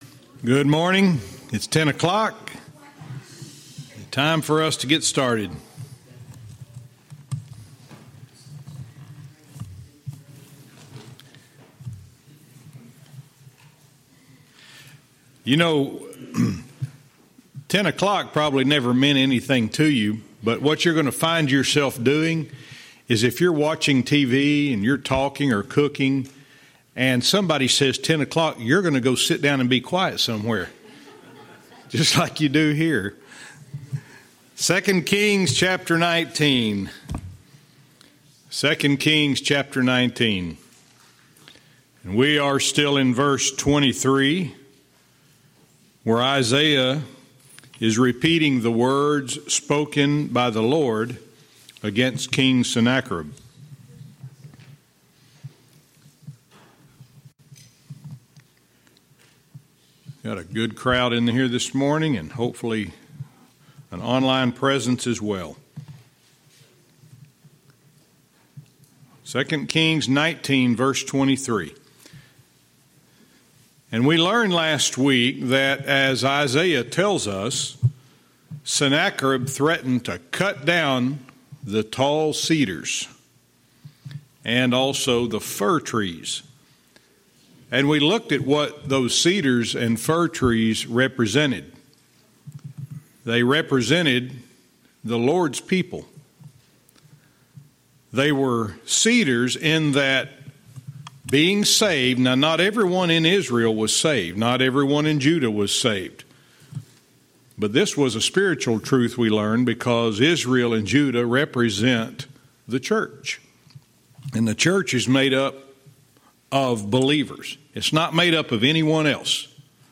Verse by verse teaching - 2 Kings 19:23(cont)-24